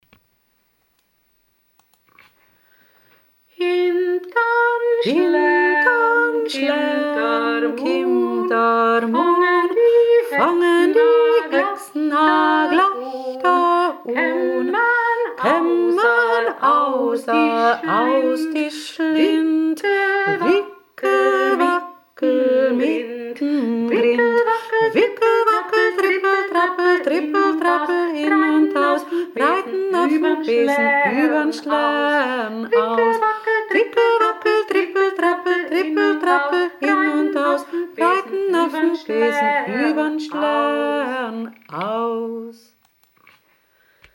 KANON langsam